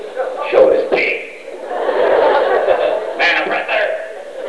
Gatecon 2003